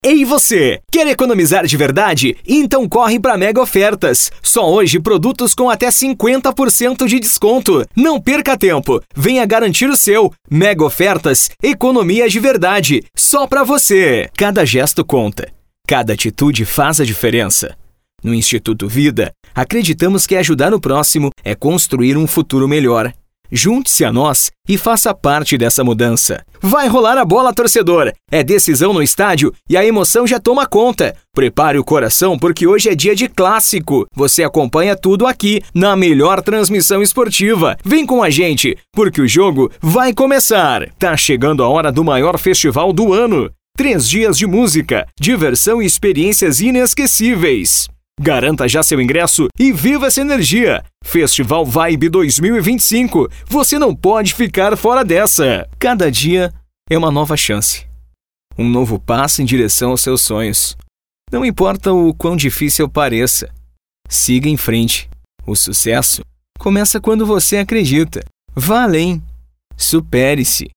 Spot Comercial
Animada